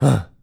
Male_Grunt_Curious_06.wav